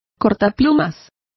Complete with pronunciation of the translation of penknife.